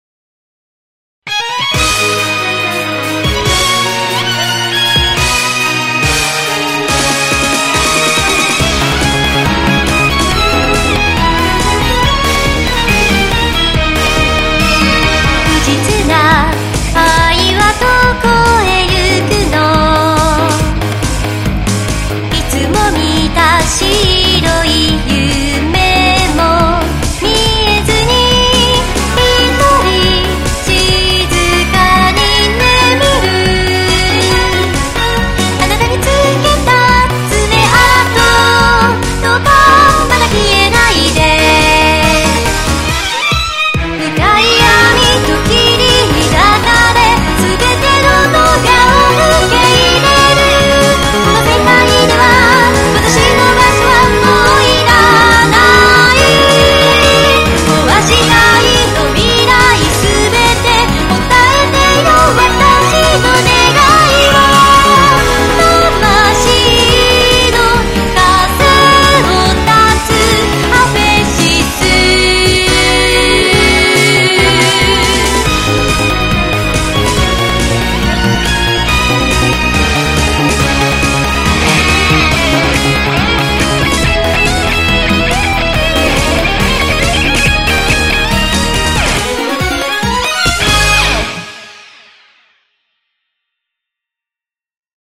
是以哥德系的日本流行音樂為題材
‧Bass：511
‧Drums：191
‧Guiter：658
‧KeyBoard：742
‧Strings：553
‧Vocal：40